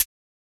Drum_Hits
Closedhat15.wav